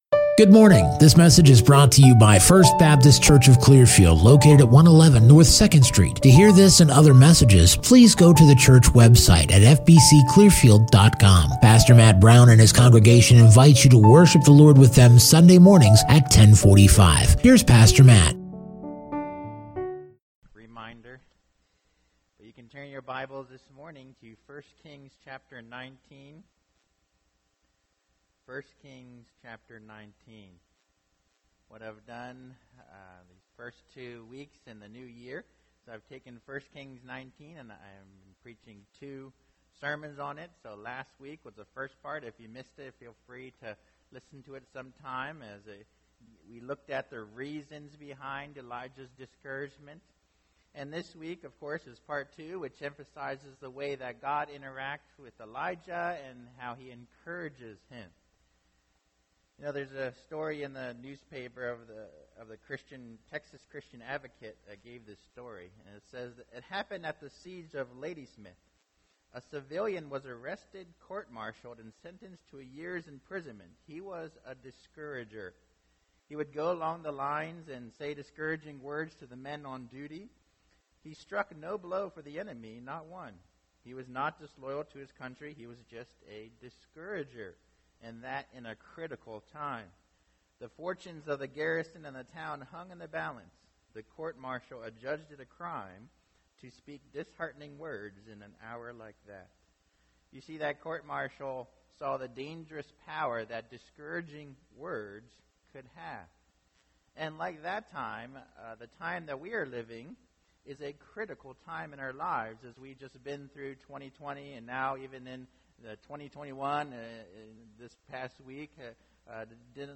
Non-Series Sermon Passage